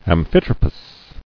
[am·phit·ro·pous]